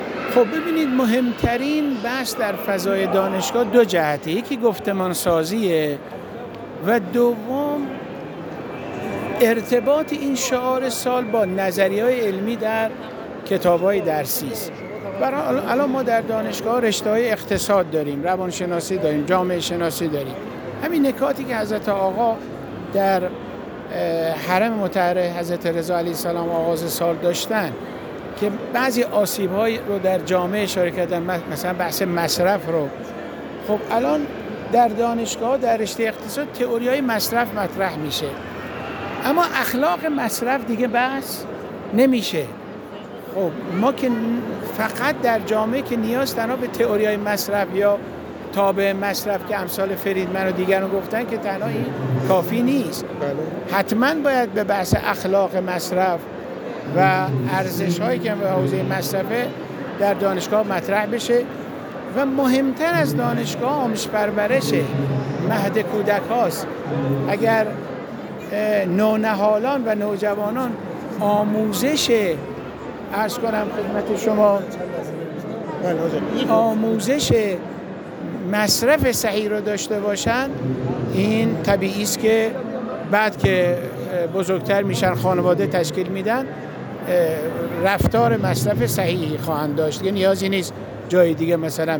حجت‌الاسلام خسروپناه در گفت‌وگو با ایکنا تأکید کرد: